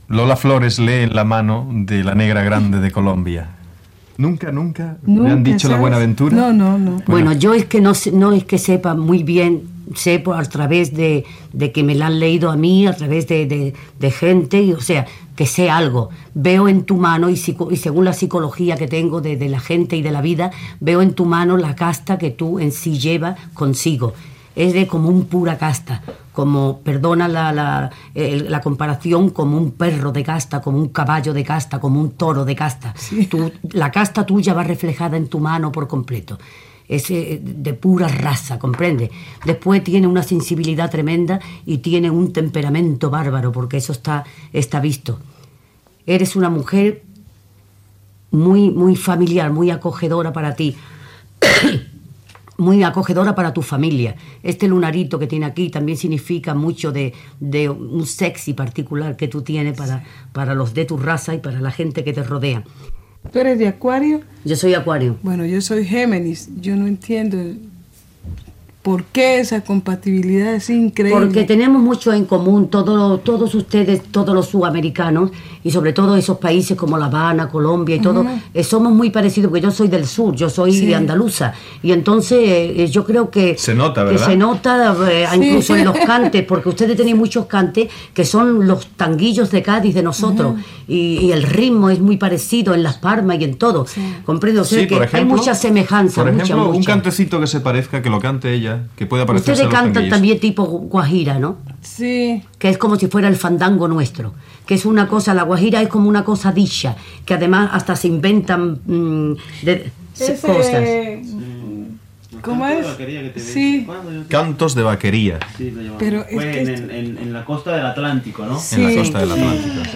La cantant Lola Flores llegeix la mà a la cantant La Negra Grande de Colòmbia (Leonor González Mina) i conversen sobre els cants colombiants
Entreteniment
Quintero, Jesús